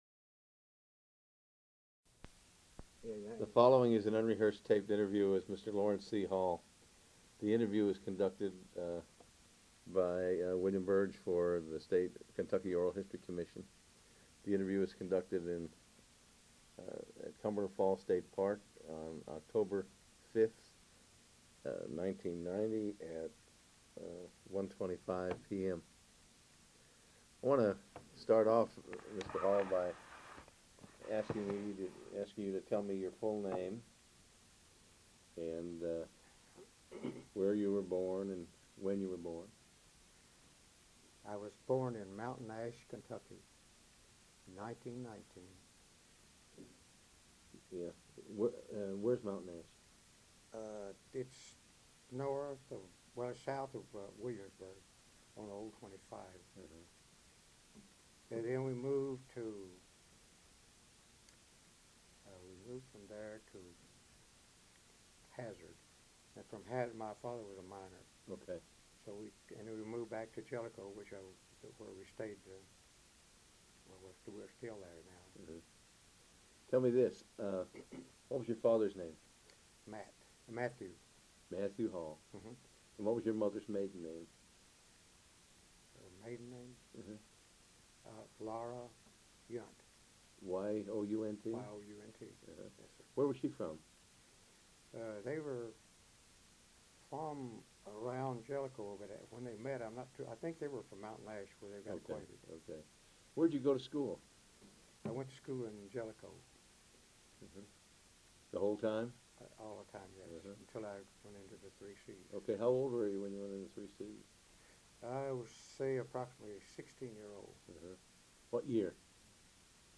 Kentucky Historical Society